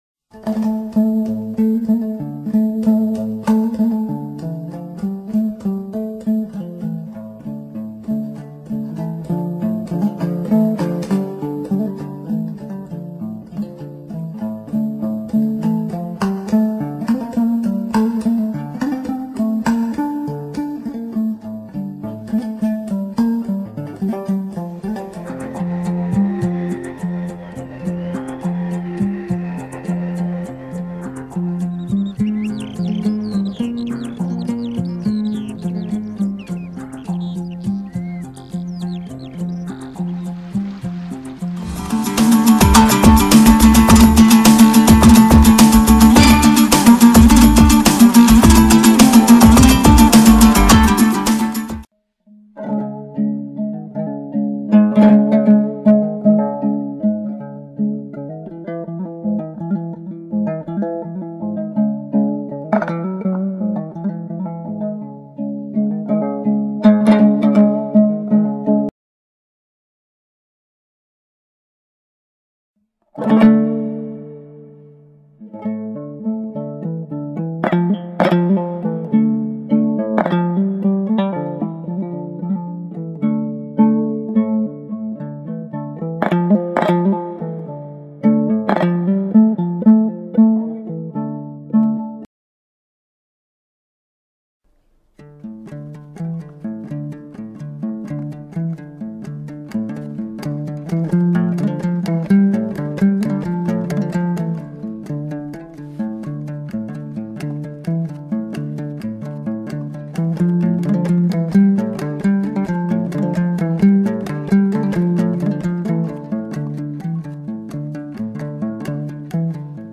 инструментальные
Народные
казахские
этнические